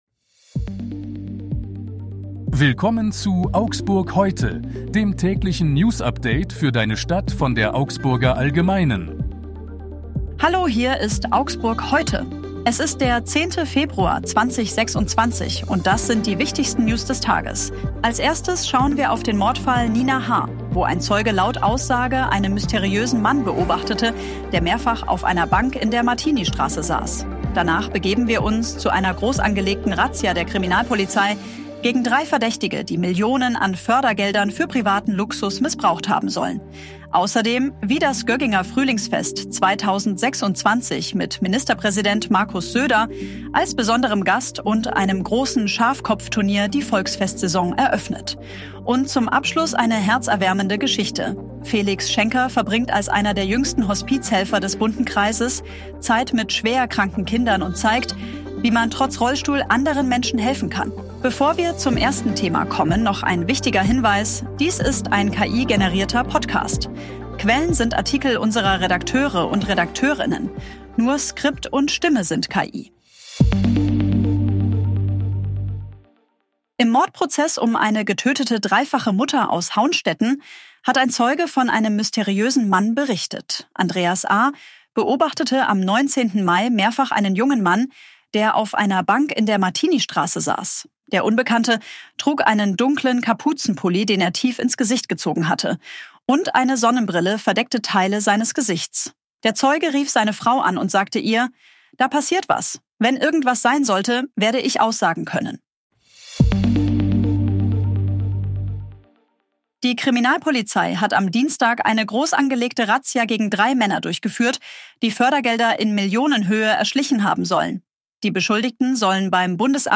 Dies ist ein KI-generierter Podcast.
Nur Skript und Stimme sind KI.